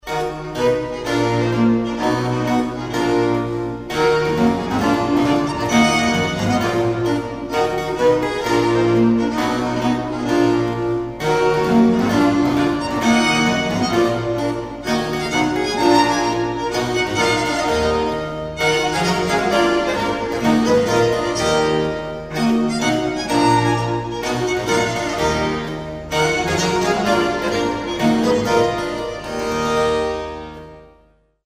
suite for violin, 2 violas & continuo in E minor